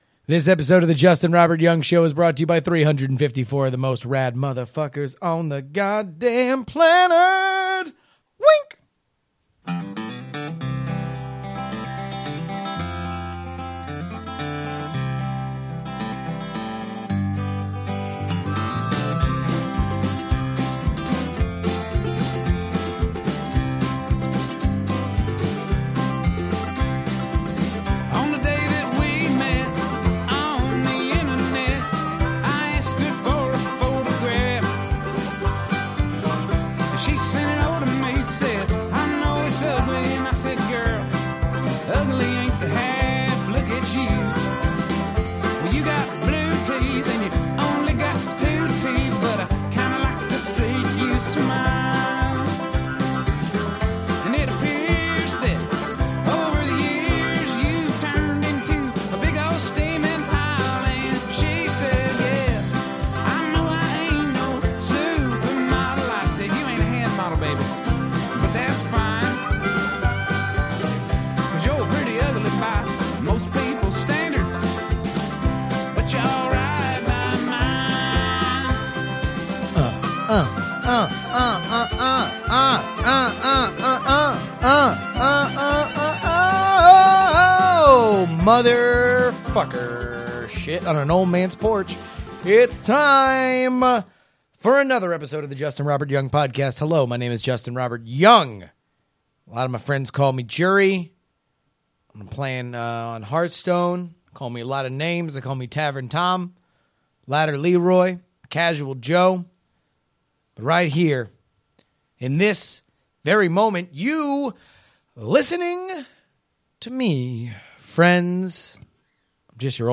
A real one mic show!